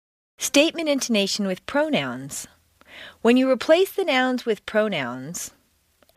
美式英语正音训练第48期:带代词的称述语调 听力文件下载—在线英语听力室